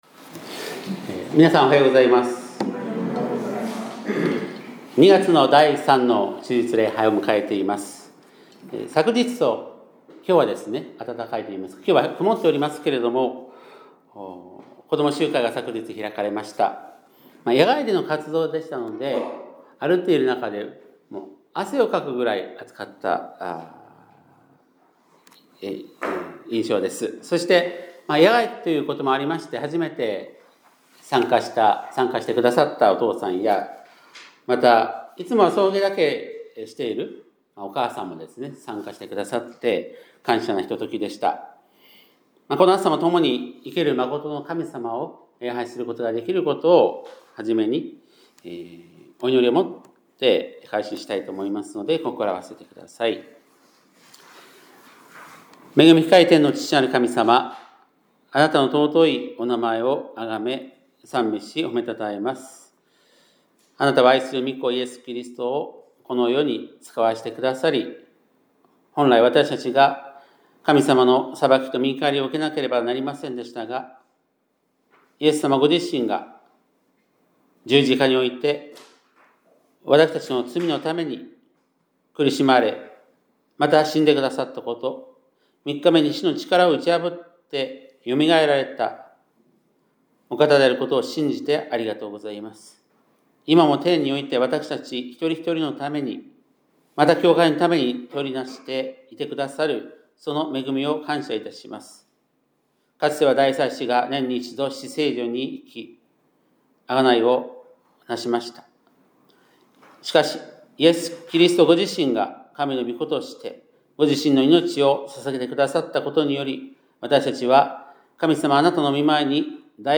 2026年2月15日（日）礼拝メッセージ - 香川県高松市のキリスト教会
2026年2月15日（日）礼拝メッセージ